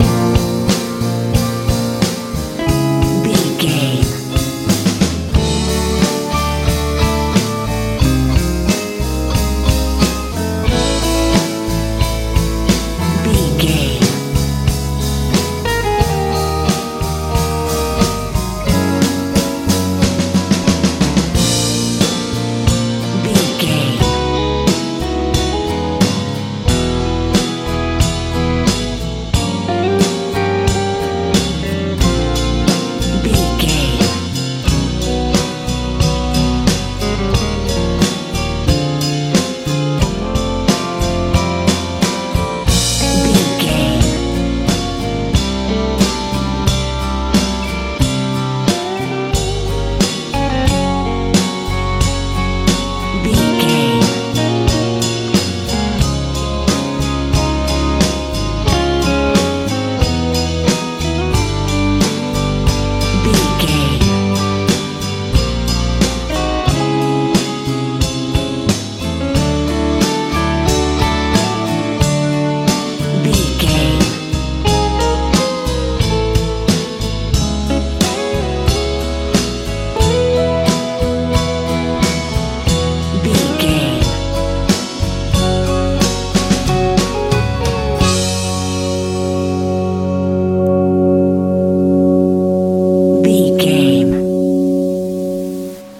root rock feel
Ionian/Major
B♭
hopeful
electric guitar
acoustic guitar
bass guitar
drums
80s
90s
uplifting